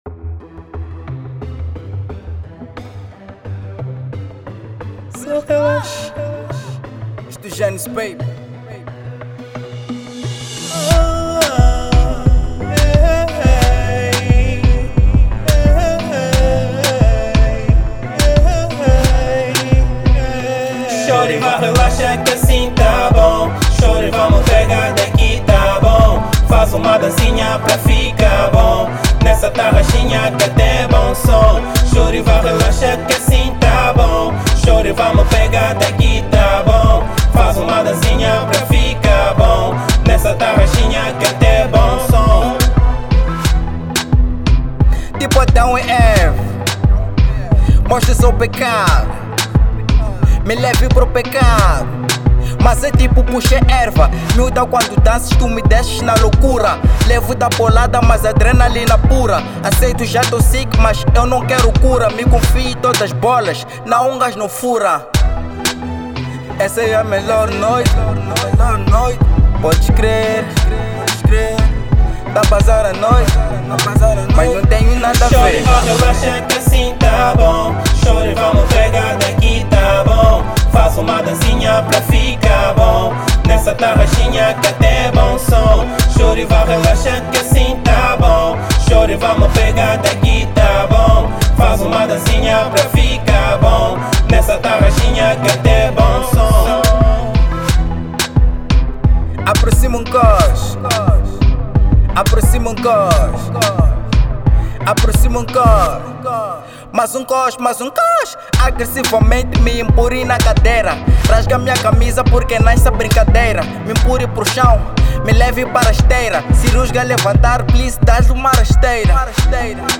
Kizomba